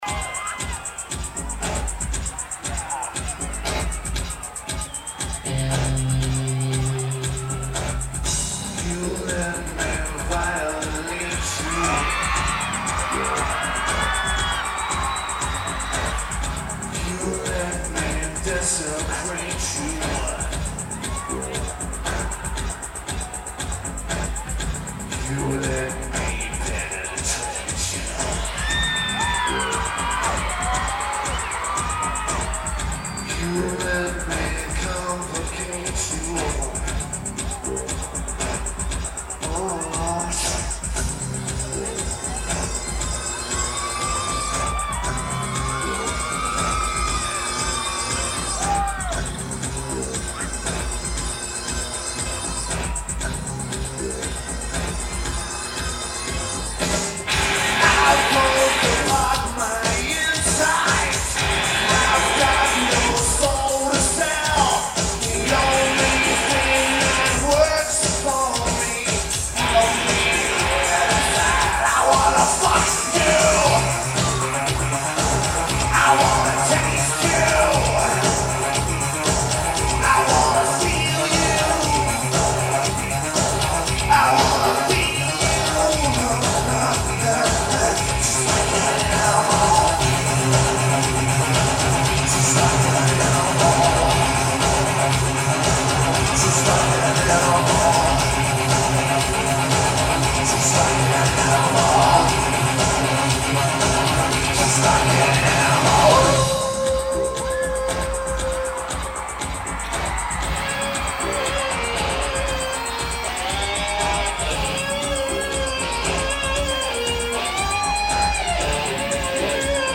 CocaCola Star Lake Amphitheater